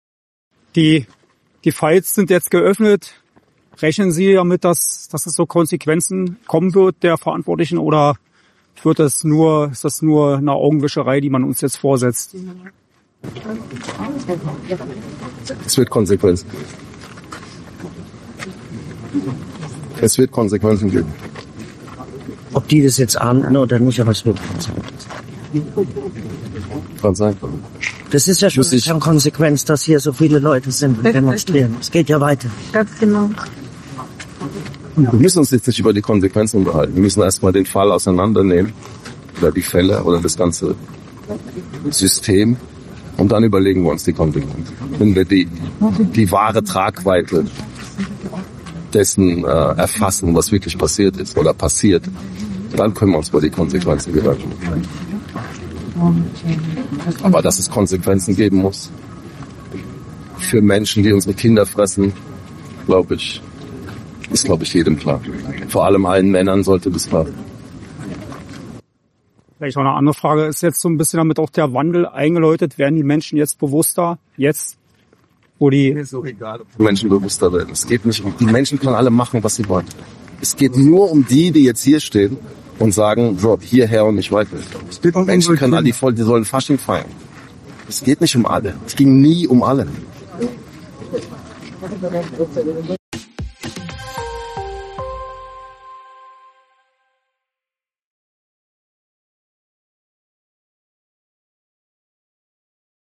fordert Musiker Xavier Naidoo. Exklusives Interview mit AUF1.